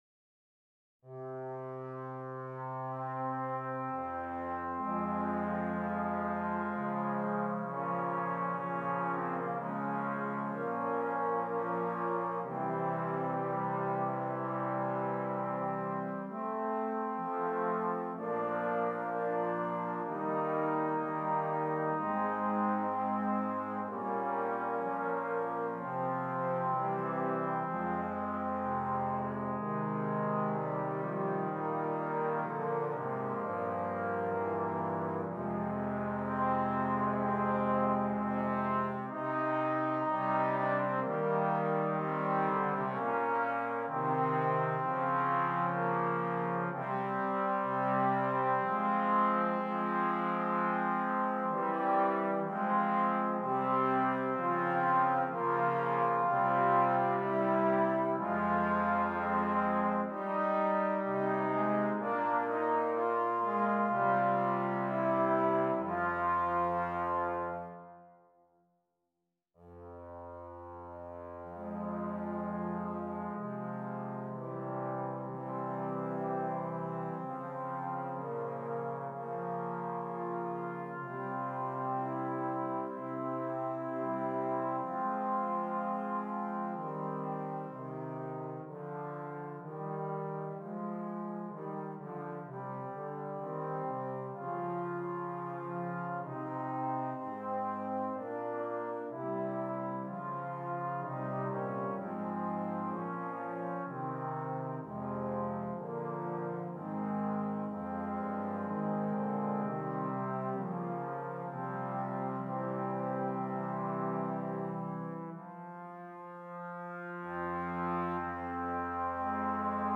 4 Trombones
arranged here for 4 trombones.